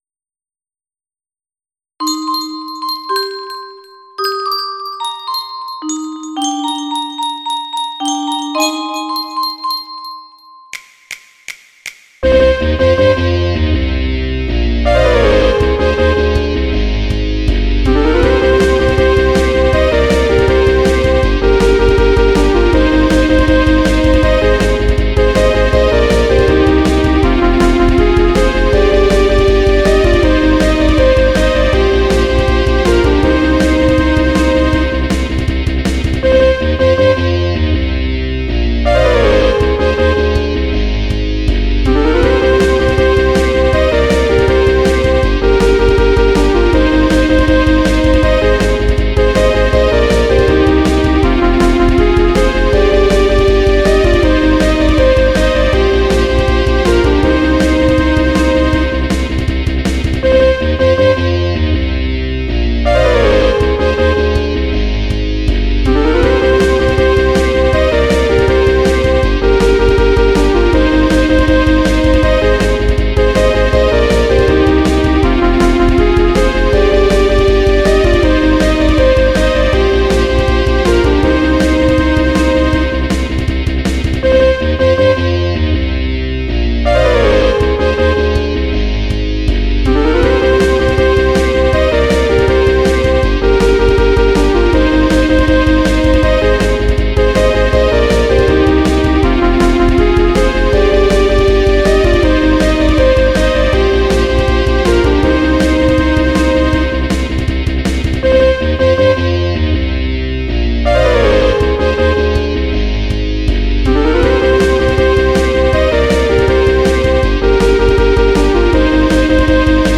アップロードサイズ制限の都合上mp3の劣化が激しいのはご愛嬌
耳コピ